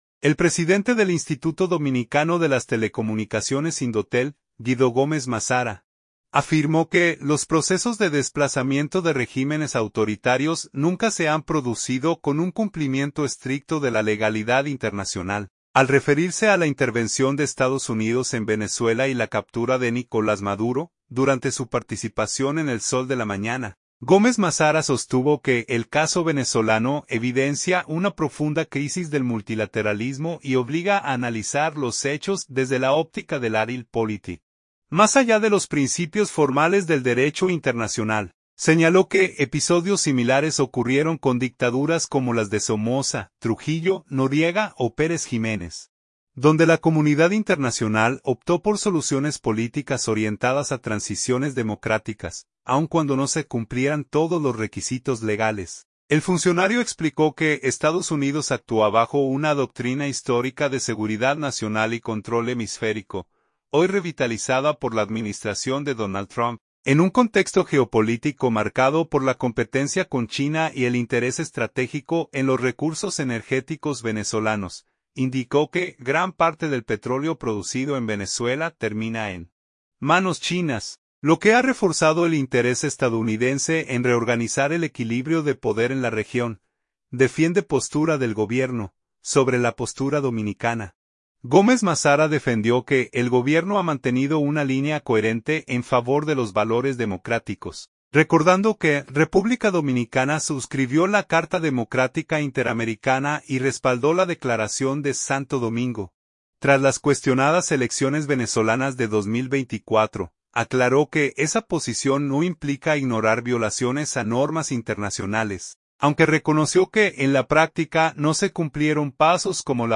El presidente del Instituto Dominicano de las Telecomunicaciones (Indotel), Guido Gómez Mazara, afirmó que los procesos de desplazamiento de regímenes autoritarios nunca se han producido con un cumplimiento estricto de la legalidad internacional, al referirse a la intervención de Estados Unidos en Venezuela y la captura de Nicolás Maduro, durante su participación en El Sol de la Mañana.